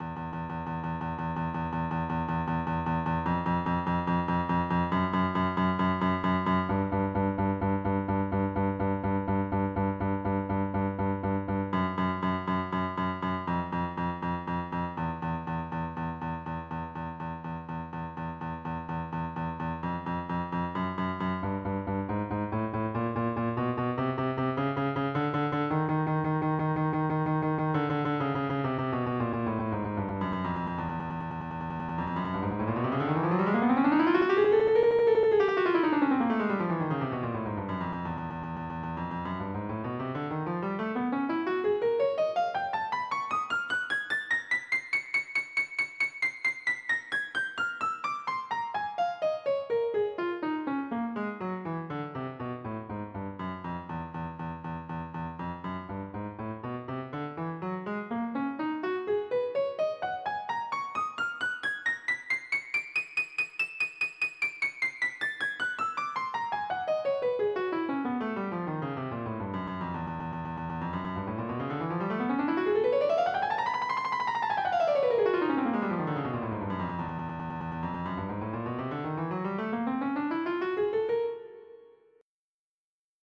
Echo Geometry Media 🎵 Sonified entropy trace (GOE Agent)
GOE_Sonification_with_Lambda_Tempo.mid.mp3